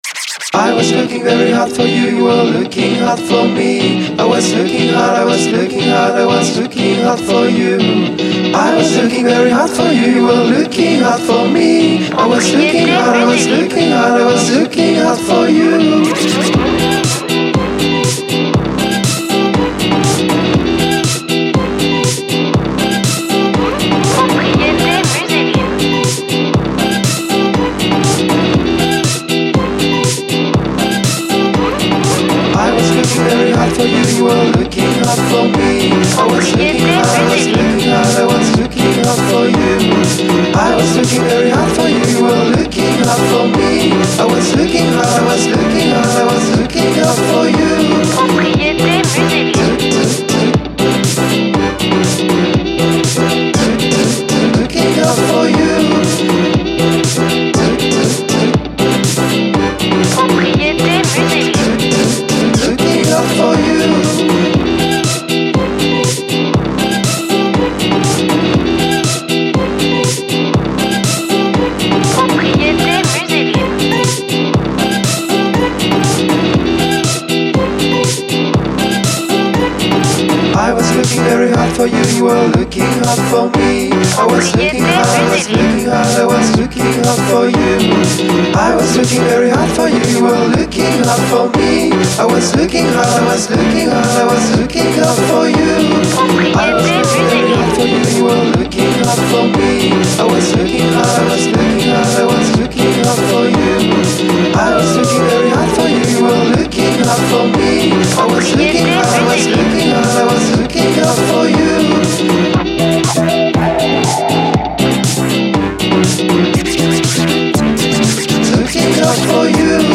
Dynamique, fun, joie